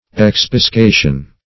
Search Result for " expiscation" : The Collaborative International Dictionary of English v.0.48: Expiscation \Ex`pis*ca"tion\, n. The act of expiscating; a fishing.